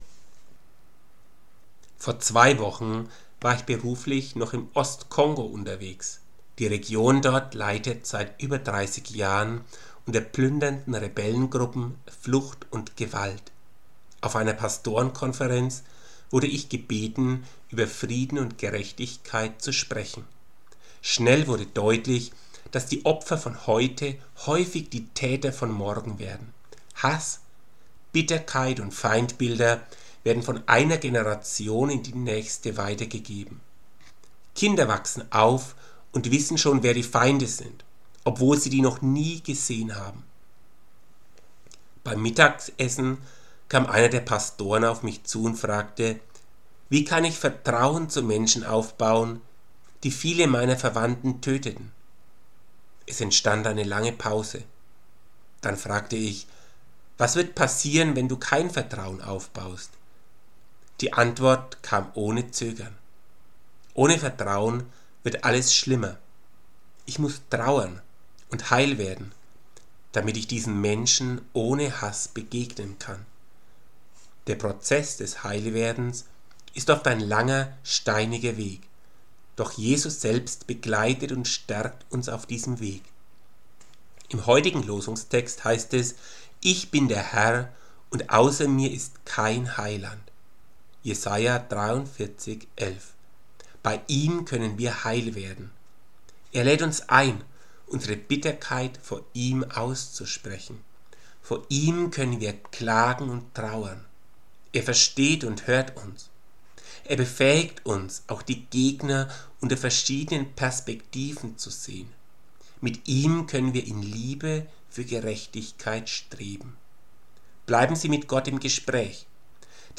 Impuls zur Tageslosung